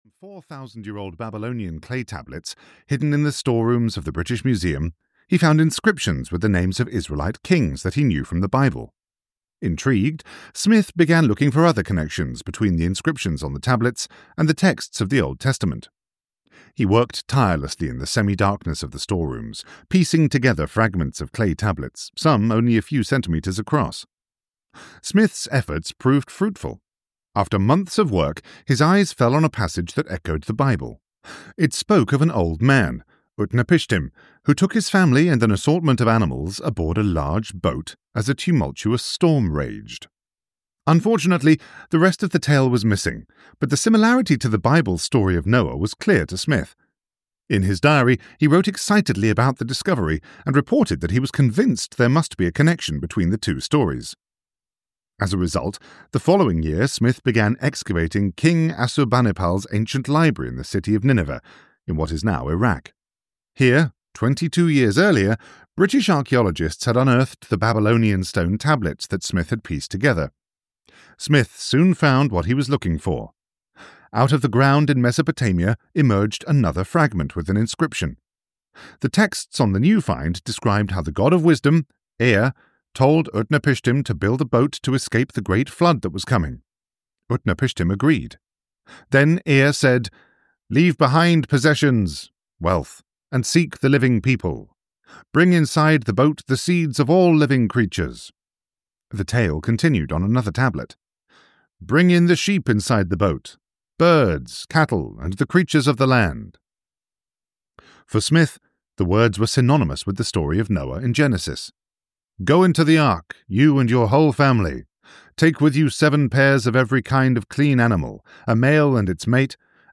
Bible History and Myth (EN) audiokniha
Ukázka z knihy